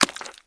STEPS Swamp, Walk 17.wav